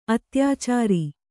♪ atyācāri